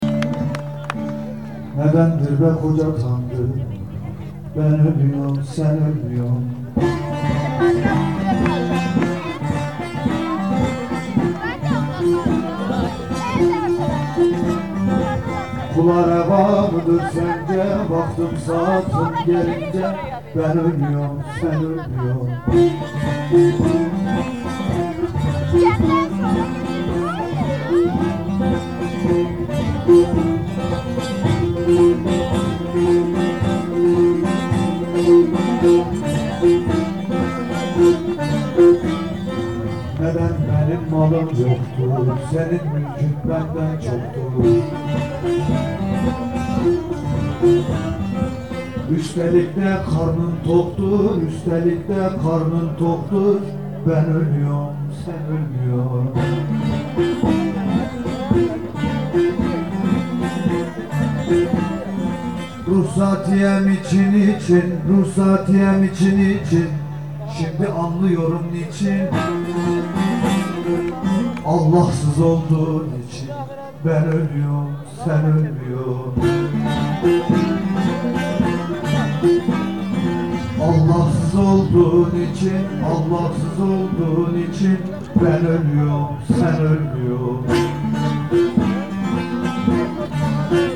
A deyiş